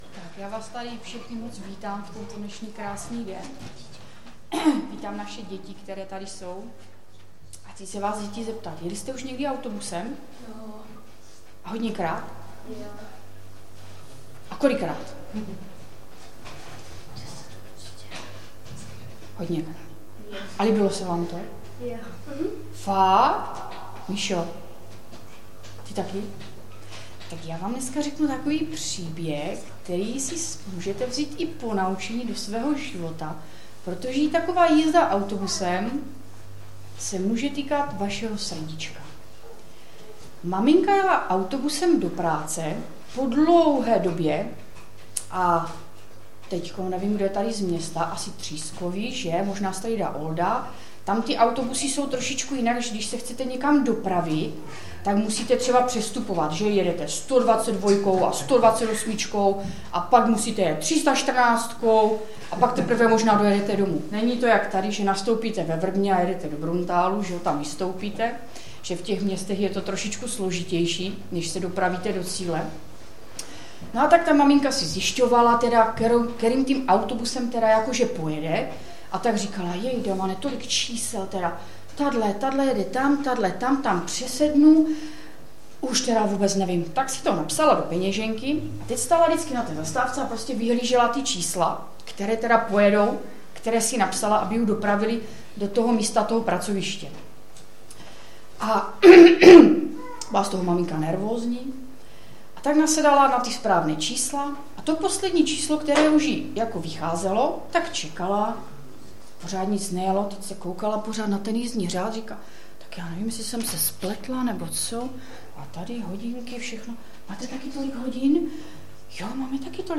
Kázání sboru CASD Vrbno pod Pradědem